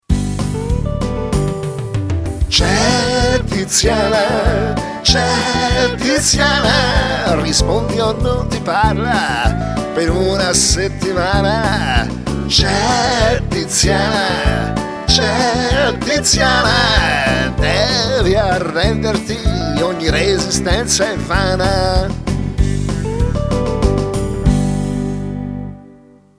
Una suoneria personalizzata che canta il nome